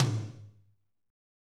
Index of /90_sSampleCDs/Northstar - Drumscapes Roland/DRM_Medium Rock/KIT_M_R Kit 2 x
TOM M R H0XL.wav